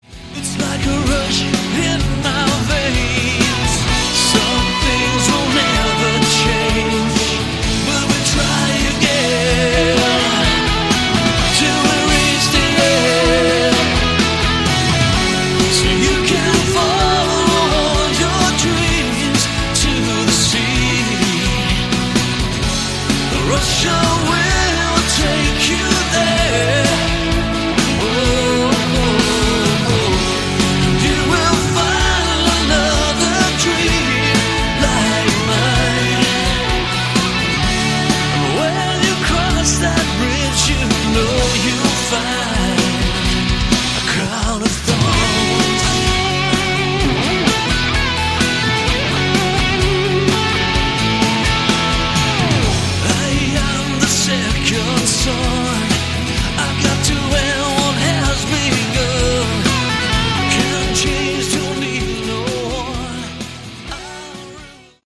Category: AOR
vocals, keyboards
guitars
drums
additional guitar solos